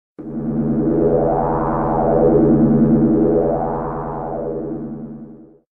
• Качество: 129, Stereo
короткие
космос
Звук космоса на телефоне